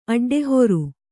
♪ aḍḍehoru